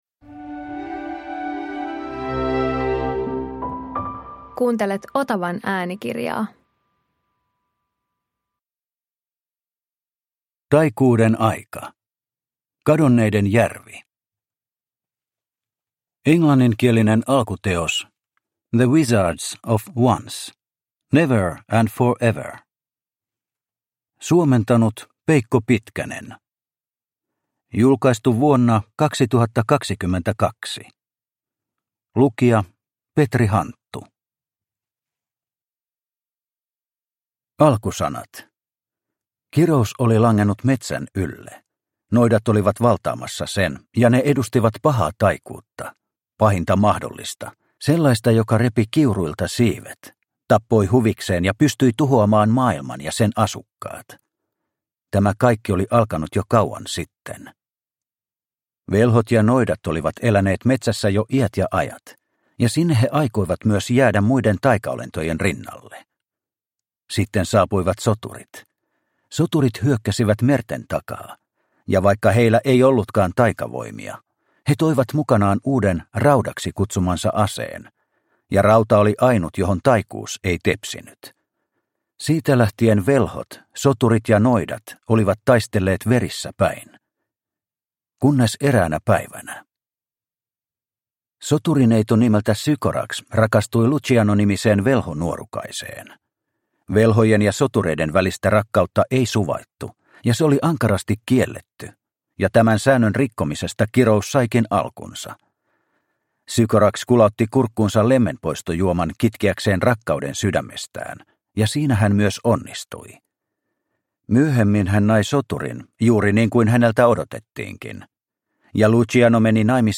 Taikuuden aika - Kadonneiden järvi – Ljudbok